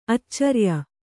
♪ accarya